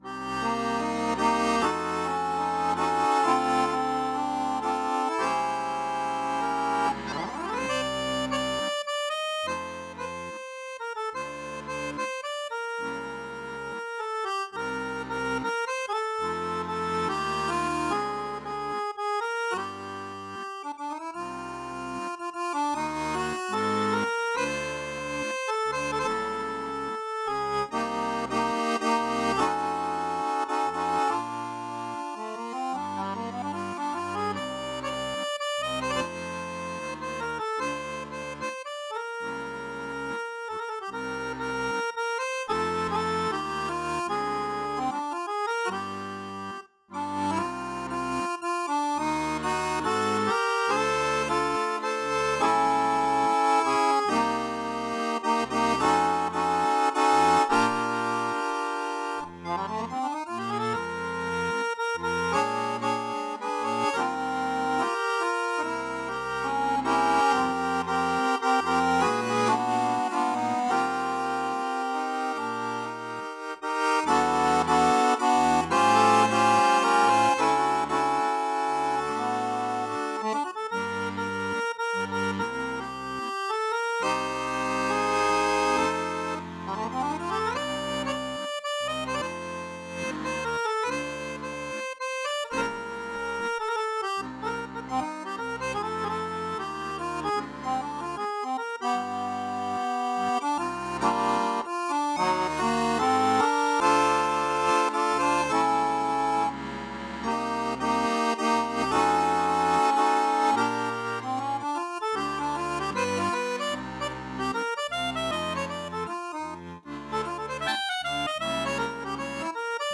Solo performances